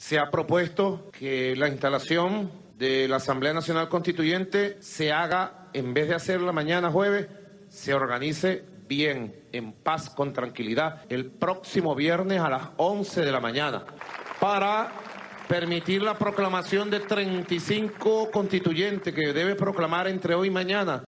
Maduro explica por qué retrasa la instalación de la Constituyente
Para que "se organice bien, en paz, con tranquilidad y todo el protocolo necesario el próximo viernes, a las 11 de la mañana (15.00 GMT) para permitir la proclamación de 35 constituyentes que deben proclamar entre hoy y mañana", explicó el mandatario durante un acto oficial con los constituyentes en Caracas.